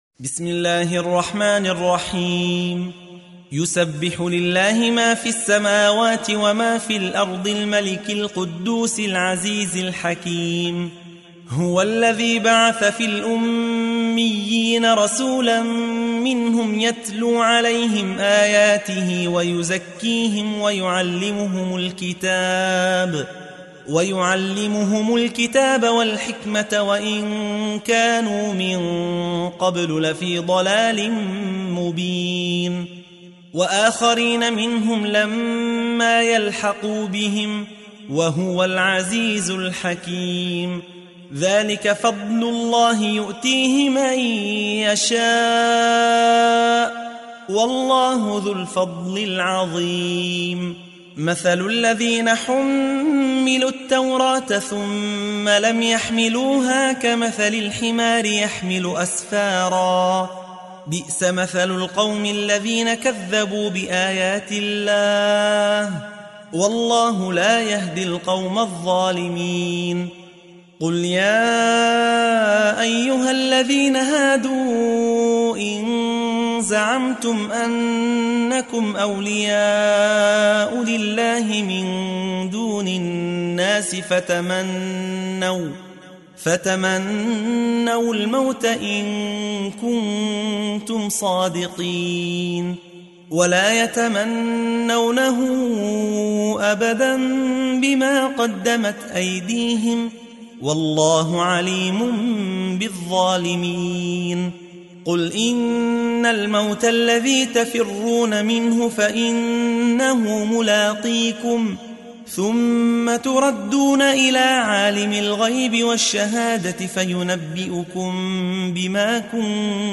تحميل : 62. سورة الجمعة / القارئ يحيى حوا / القرآن الكريم / موقع يا حسين